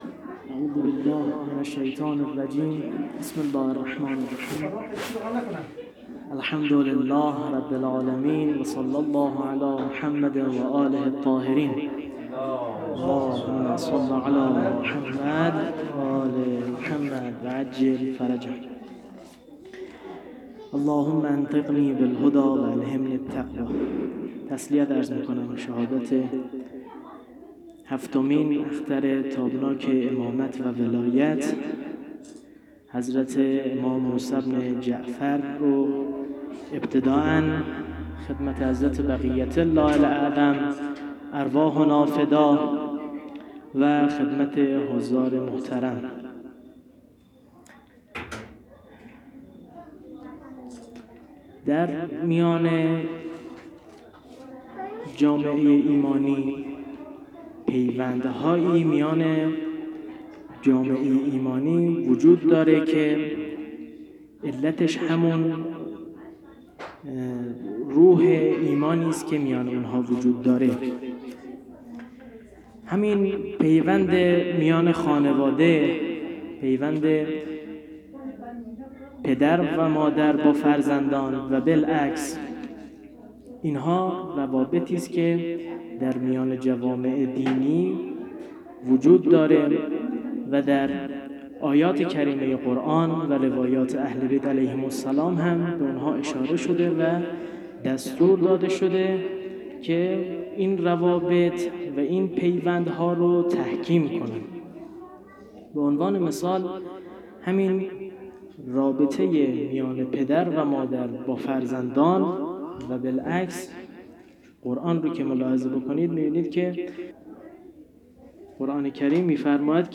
سخنرانی شام غریبان شهادت امام کاظم علیه السلام.mp3
سخنرانی-شام-غریبان-شهادت-امام-کاظم-علیه-السلام.mp3